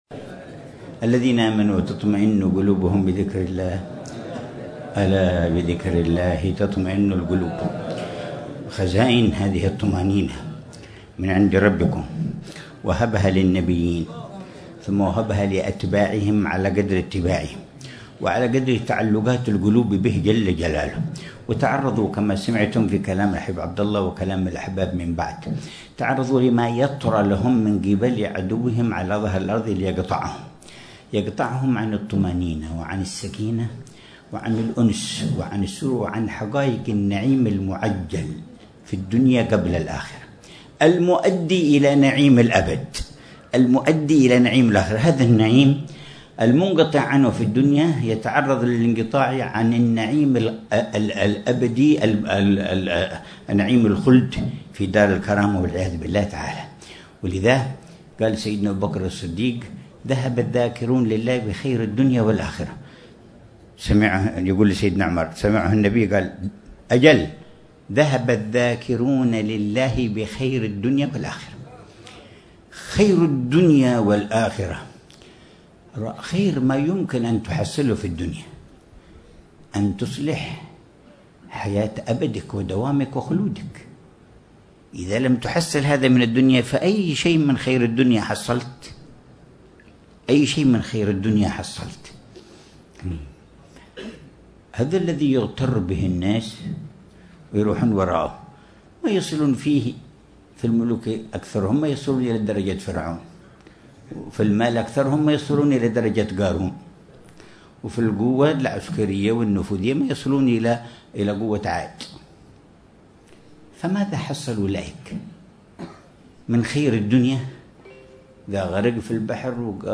محاضرة
بمدينة المكلا، ساحل حضرموت